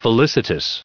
1483_felicitous.ogg